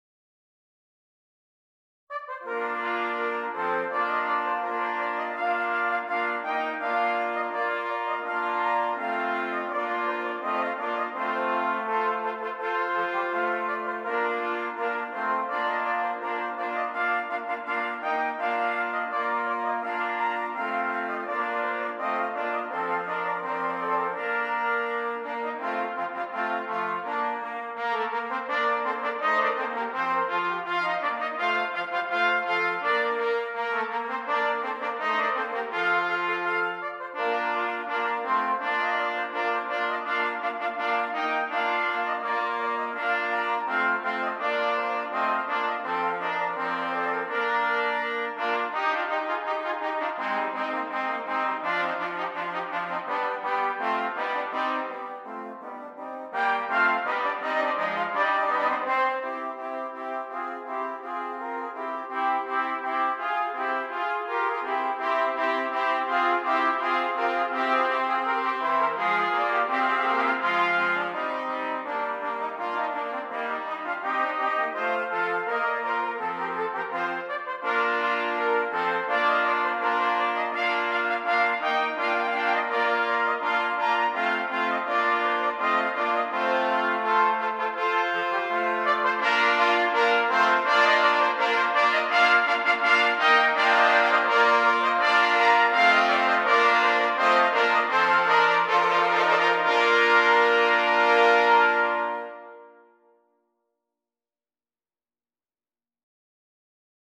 Brass Band
5 Trumpets